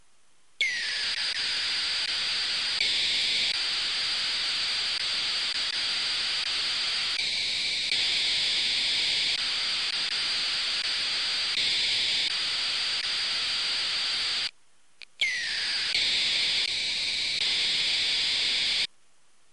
La musique a cessé de joue et est remplacé par du
RSHHHH SHH SHHHH SH.
De son côté, la "musique" continue de gricher mais encore moins fort qu'hier.
rshhh.MP3